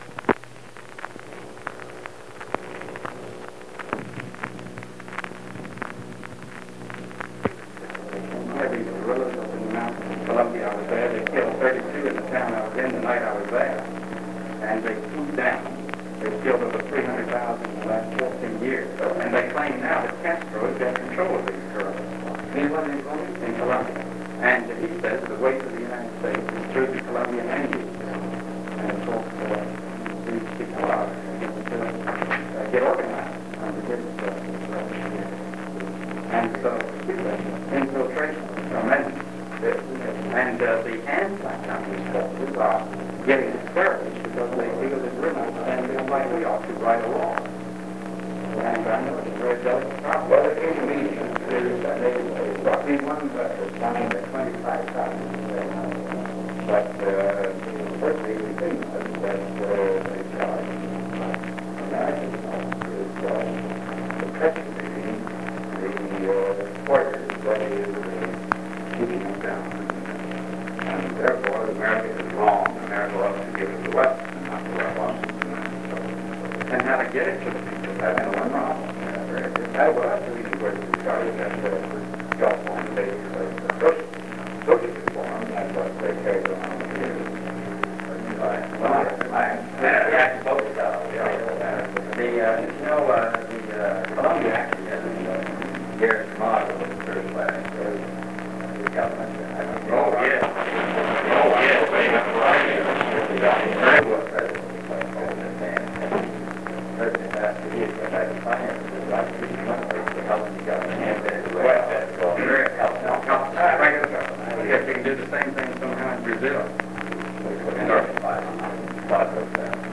Secret White House Tapes | John F. Kennedy Presidency Meeting with Billy Graham and Dwight Eisenhower Rewind 10 seconds Play/Pause Fast-forward 10 seconds 0:00 Download audio Previous Meetings: Tape 121/A57.